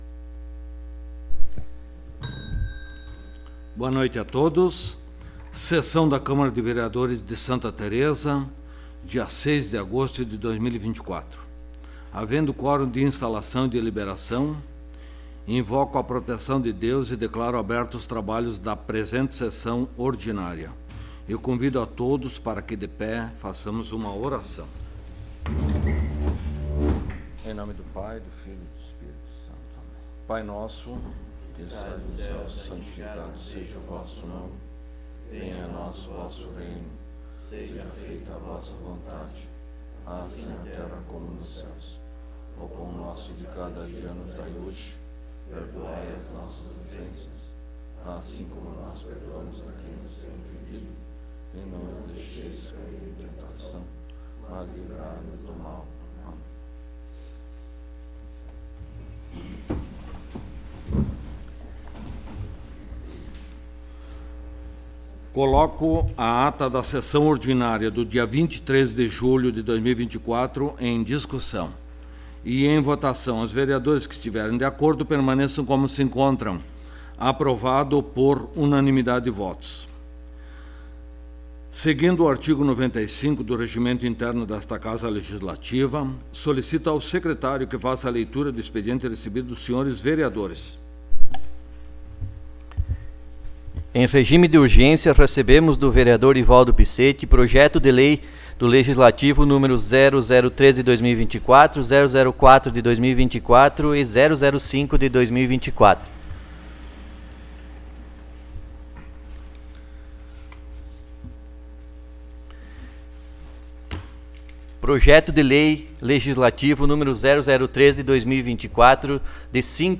Áudio da Sessão
Local: Câmara Municipal de Vereadores de Santa Tereza